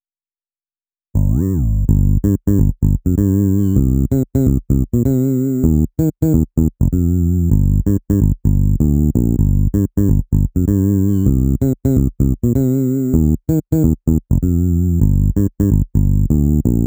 VTDS2 Song Kit 09 Male Going Crazy E Bass.wav